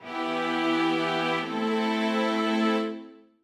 短調の正格終止：ミ・シミソからラ・ドミラ
c1-cadence-DT-Nm.mp3